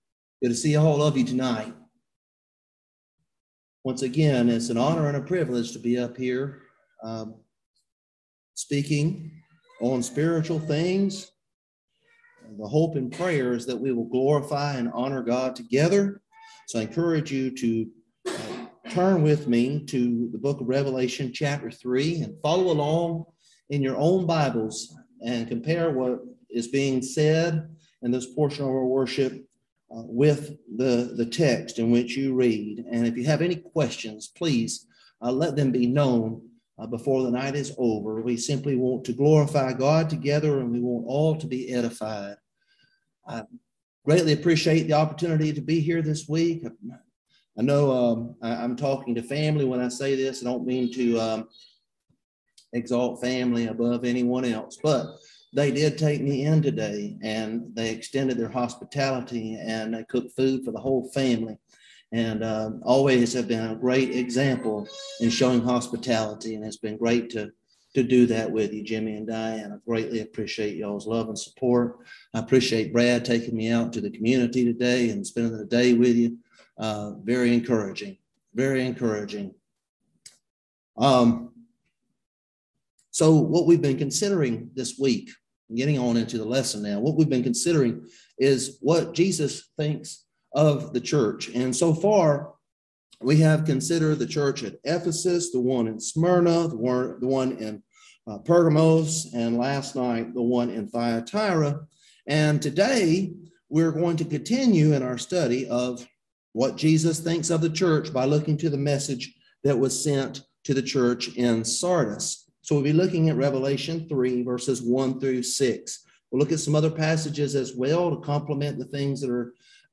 Passage: Revelation 3:1-6 Service Type: Gospel Meeting